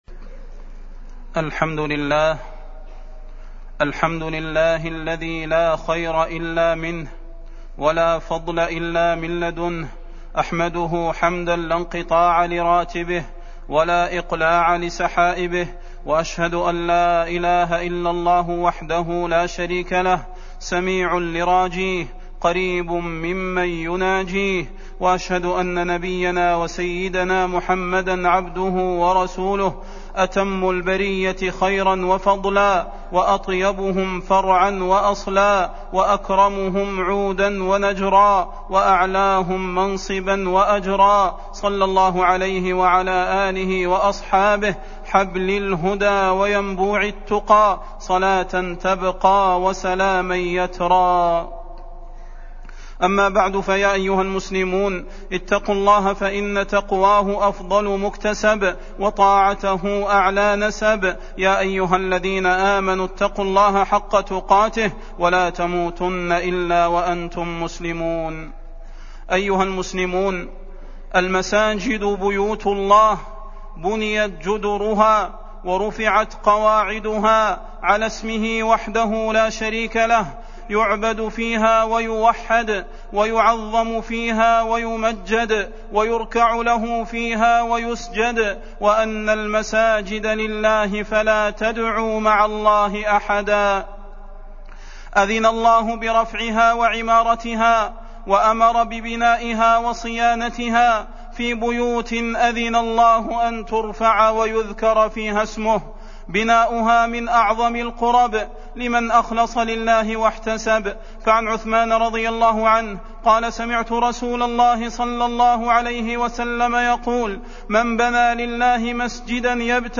فضيلة الشيخ د. صلاح بن محمد البدير
تاريخ النشر ٢٦ شوال ١٤٢٧ هـ المكان: المسجد النبوي الشيخ: فضيلة الشيخ د. صلاح بن محمد البدير فضيلة الشيخ د. صلاح بن محمد البدير أحكام المساجد